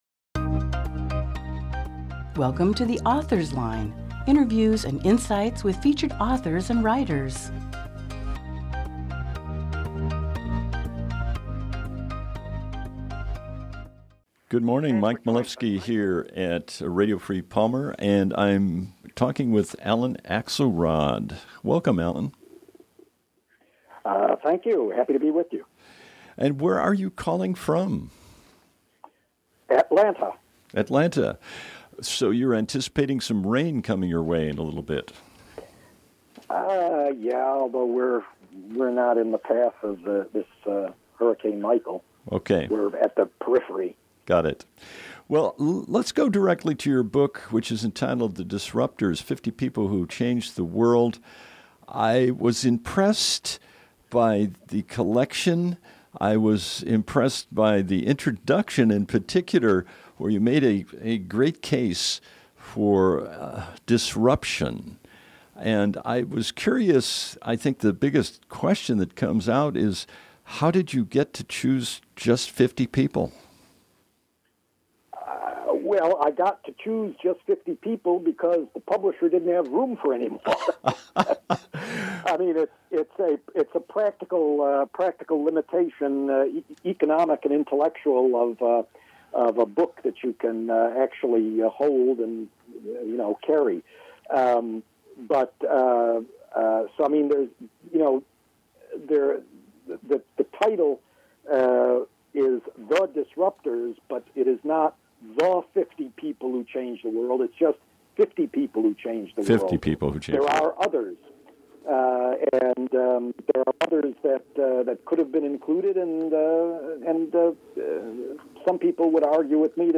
Oct 12, 2018 | Author Interviews